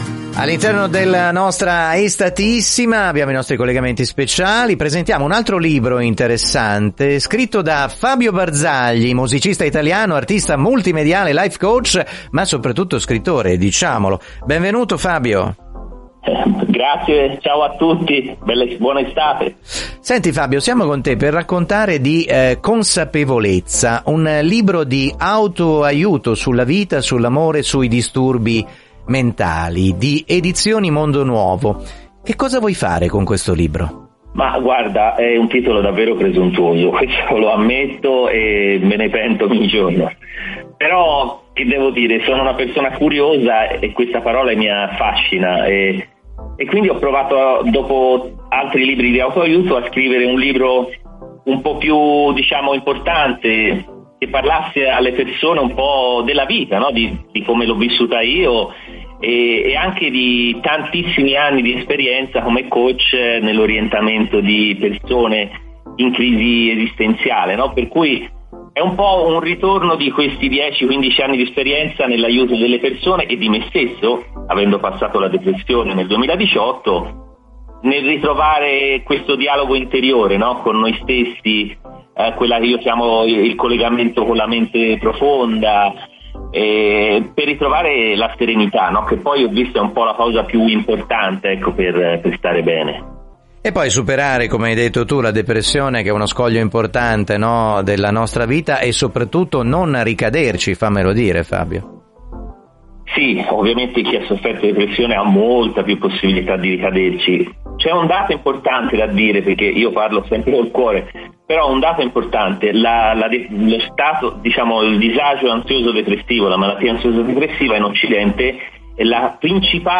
04:28 consapevolezza un libro di auto aiuto - intervista